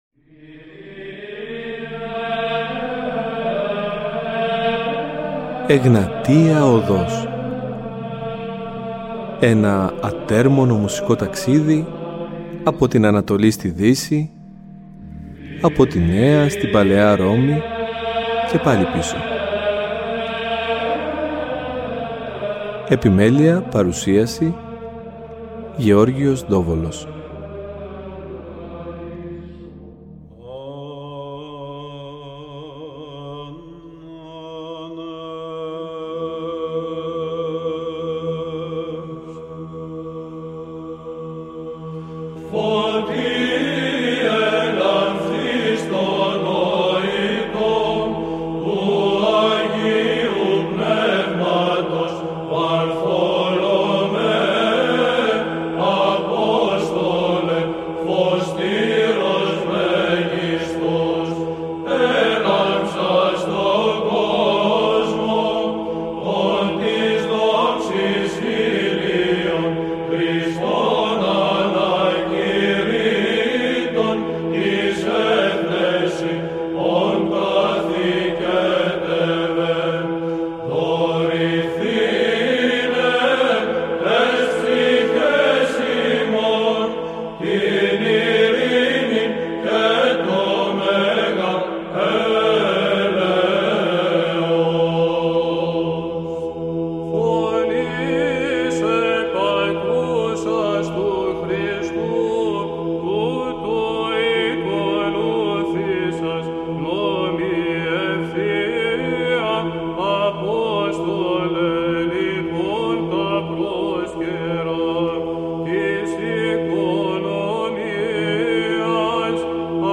Βυζαντινη Μουσικη
Εκκλησιαστικη Μουσικη